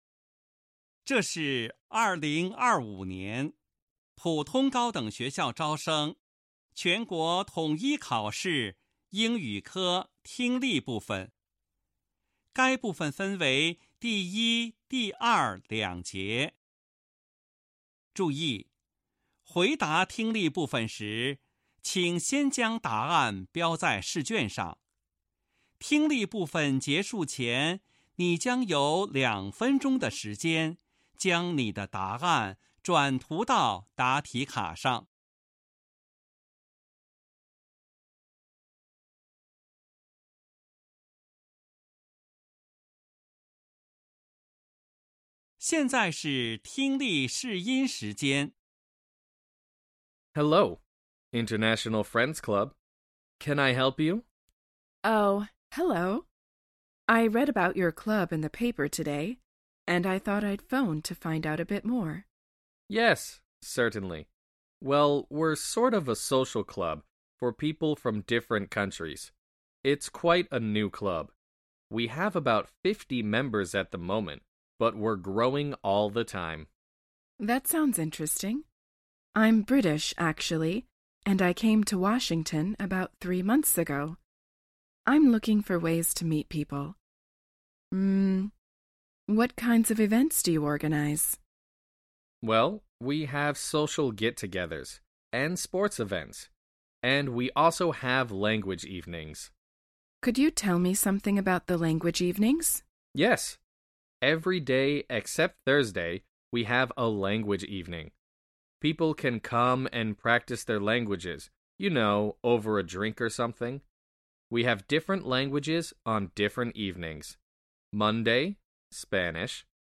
2025年普通高等学校招生全国统一考试英语听力.mp3